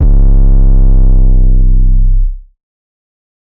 808 (Excalibur).wav